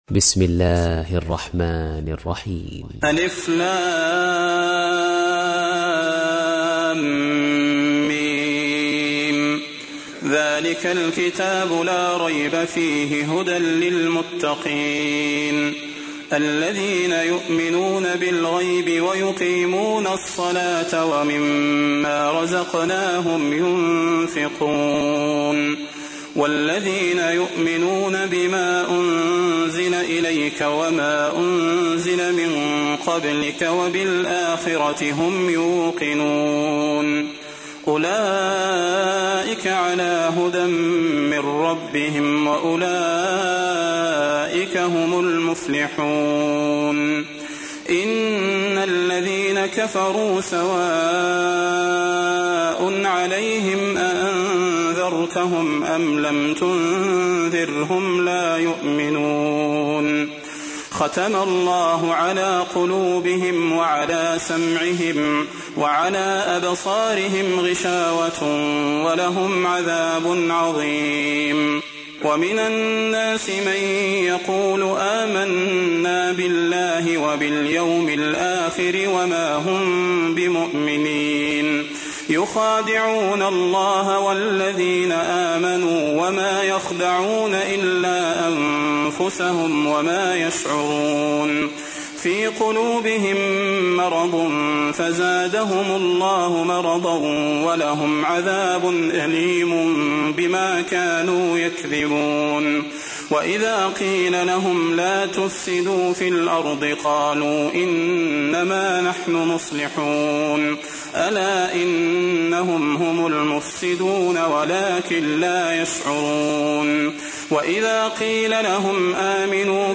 تحميل : 2. سورة البقرة / القارئ صلاح البدير / القرآن الكريم / موقع يا حسين